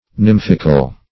Search Result for " nymphical" : The Collaborative International Dictionary of English v.0.48: Nymphic \Nymph"ic\, Nymphical \Nymph"ic*al\, a. [Gr.
nymphical.mp3